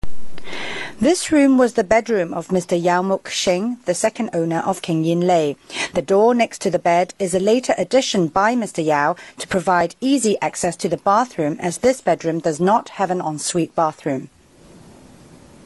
Vocal Description